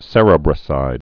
(sə-rēbrə-sīd, sĕrə-brə-sīd)